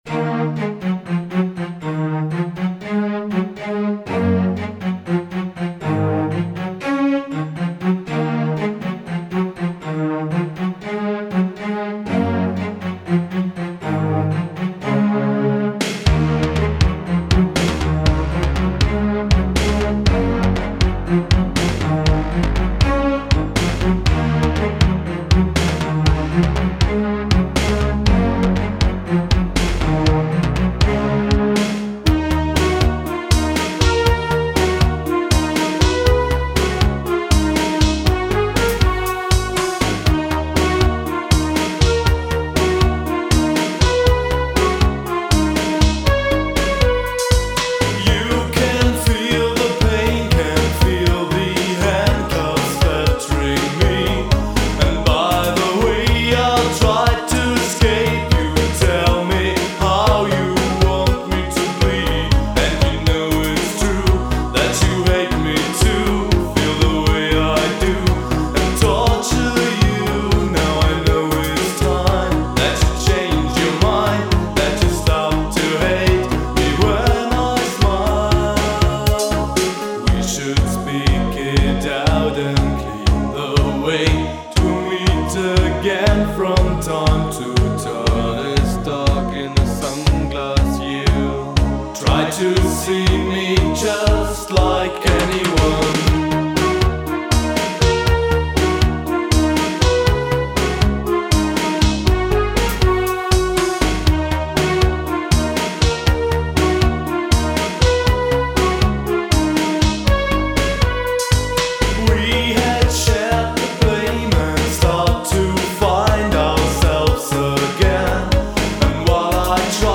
Diese Version haben sie 1989 im Synsound Studio Brüssel